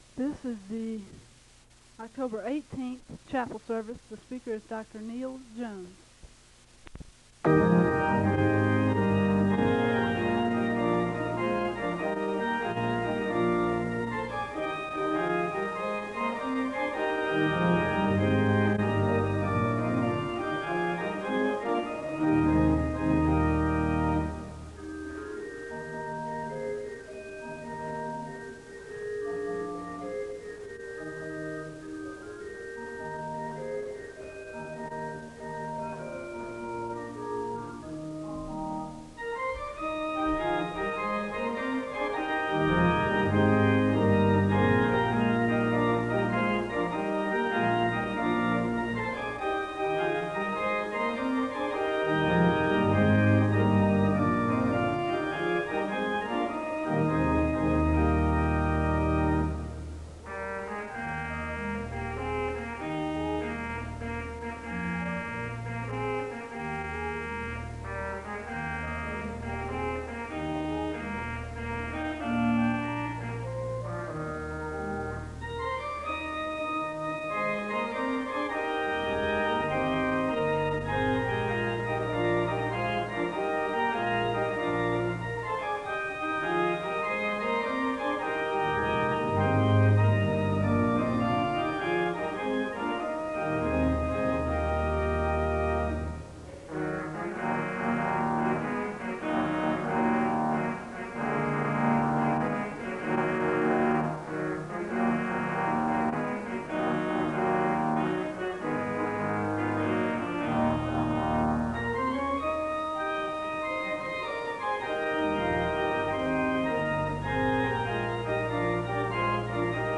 The service begins with organ music (00:00-05:30).
The choir sings the anthem (09:42-13:12).
Location Wake Forest (N.C.)
SEBTS Chapel and Special Event Recordings SEBTS Chapel and Special Event Recordings